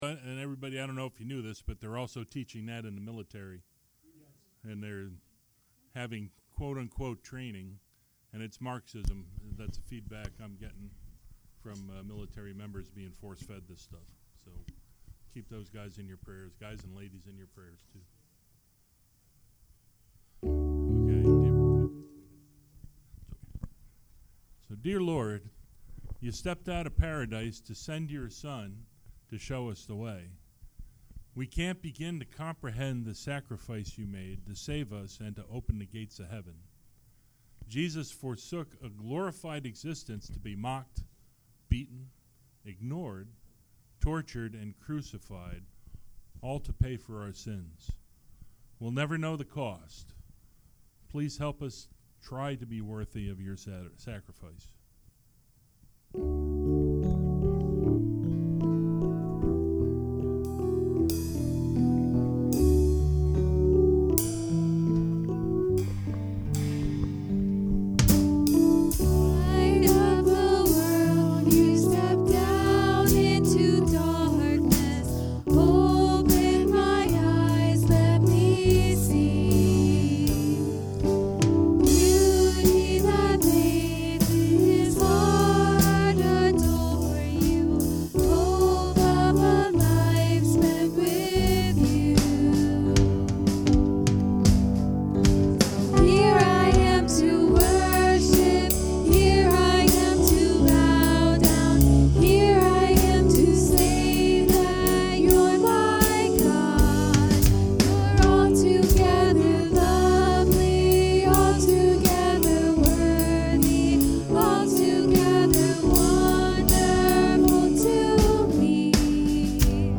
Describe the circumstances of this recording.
Acts 20:28-38 Service Type: Sunday Morning Worship No one knows what the future may hold for us as Christ followers.